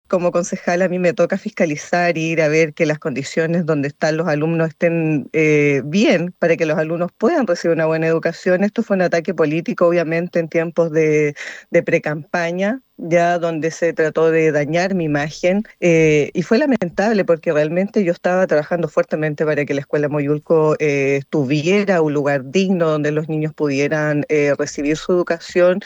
En conversación con Radio Bío Bío, Viviana Díaz dijo que fue un ataque político en tiempo de elecciones, señalando que se dañó su imagen al buscar su reelección en el cargo.